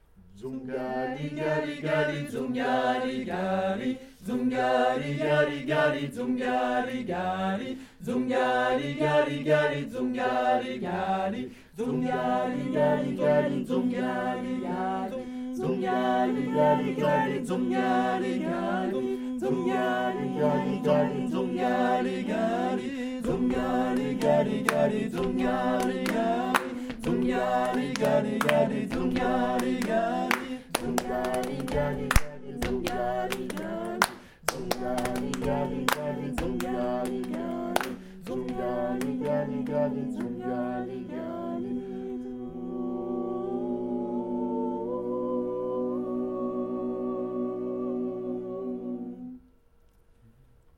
Mises en situation : production réalisée lors de journée de formation disciplinaire (réforme et nouveaux programmes)